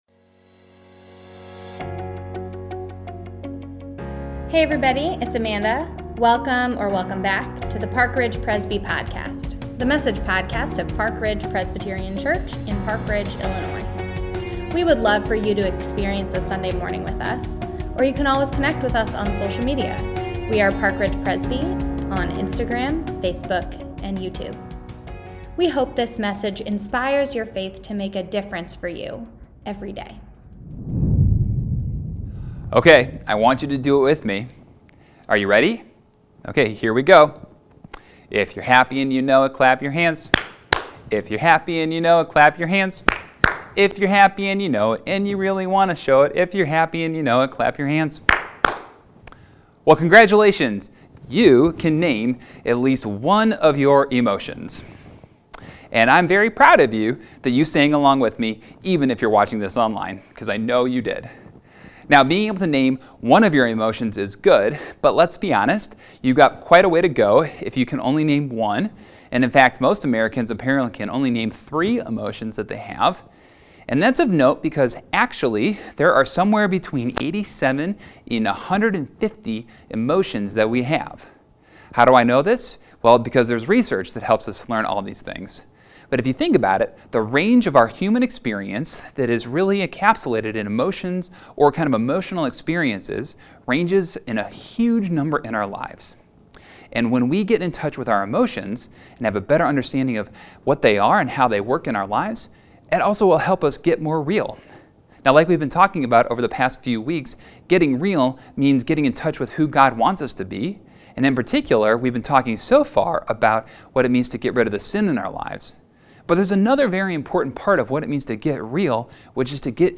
Get Real Week 3 | Online Worship 10am | March 20, 2022 | Lent 2022